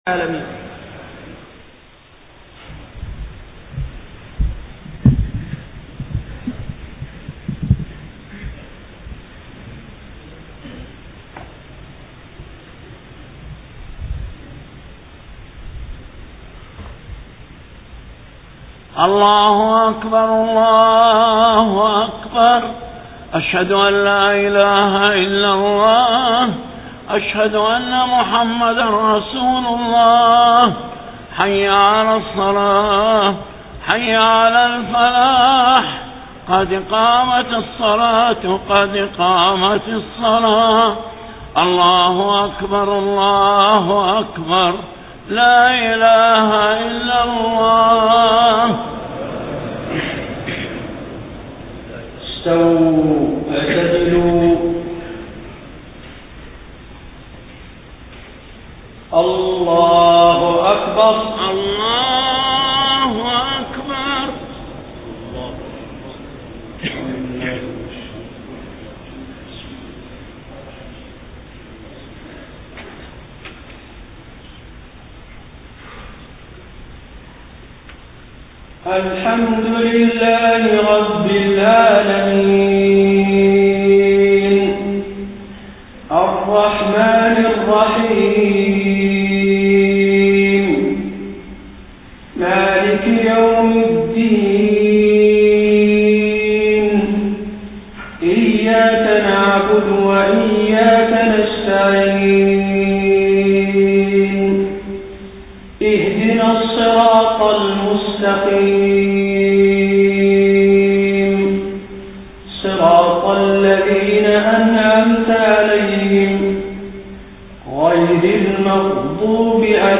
صلاة الجمعة 5 ربيع الأول 1431هـ سورتي العصر و الإخلاص > 1431 🕌 > الفروض - تلاوات الحرمين